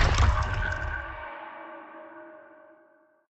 CosmicRageSounds / ogg / general / combat / aircraft / directional.ogg